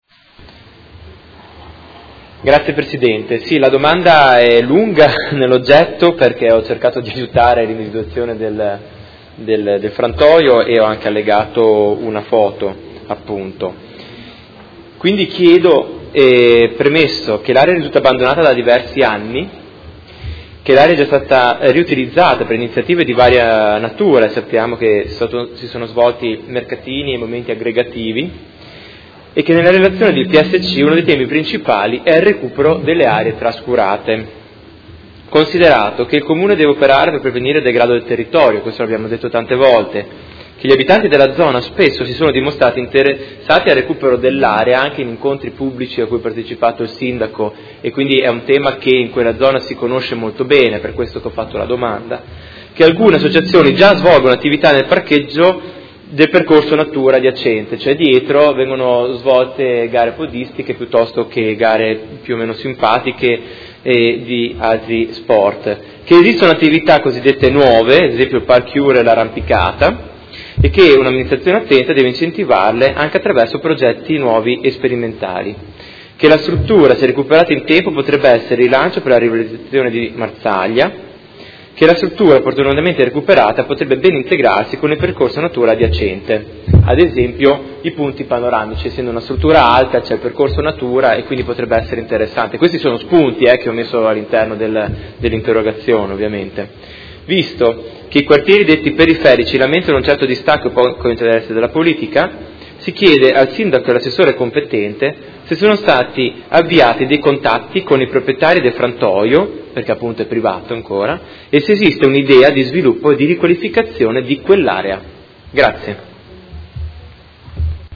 Seduta del 10/12/2015. Interrogazione del Consigliere Chincarini (Per Me Modena) avente per oggetto: Quali sono le reali intenzioni dell’Amministrazione a proposito del vecchio frantoio che si trova nell’area del Parco Fluviale a Marzaglia Vecchia in fondo a Via della Chiesa?